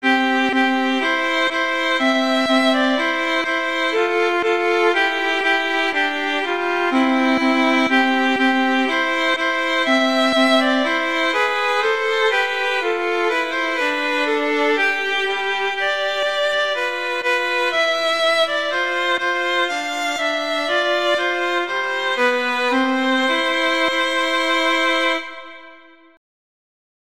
arrangements for two violins
two violins